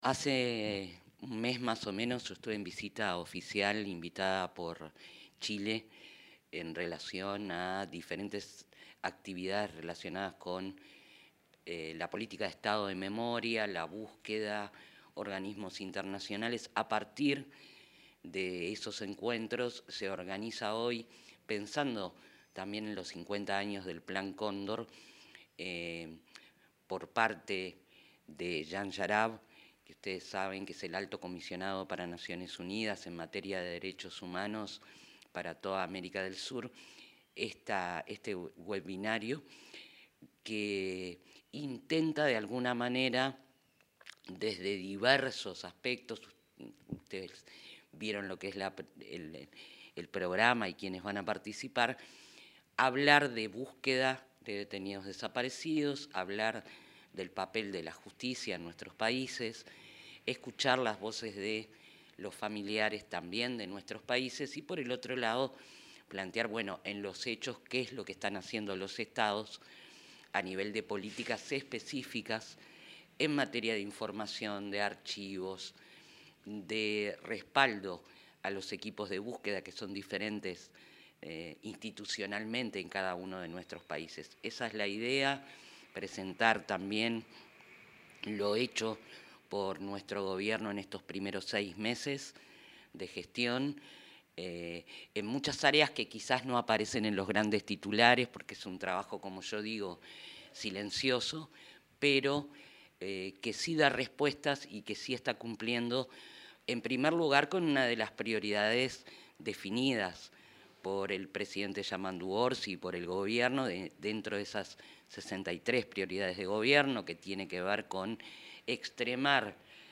Declaraciones de la titular de la Secretaría de Derechos Humanos para el Pasado Reciente, Alejandra Casablanca